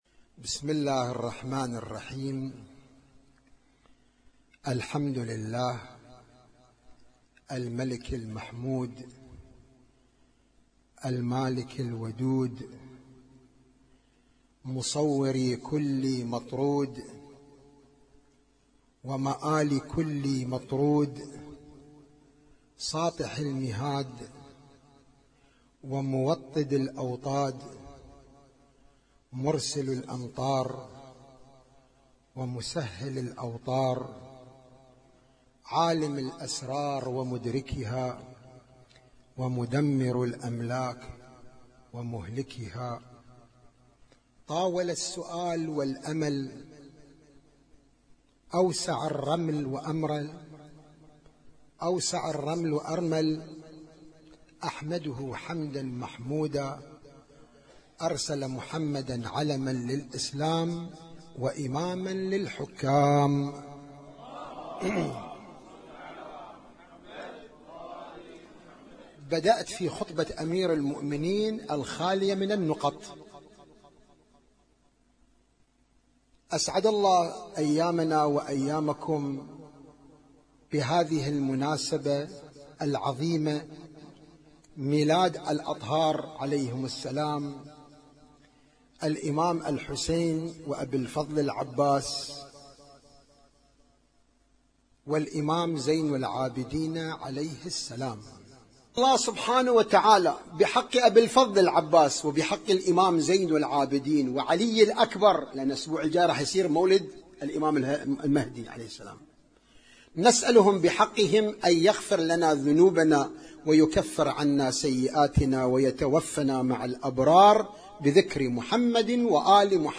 اسم التصنيف: المـكتبة الصــوتيه >> المواليد >> المواليد 1437